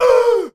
UHH.wav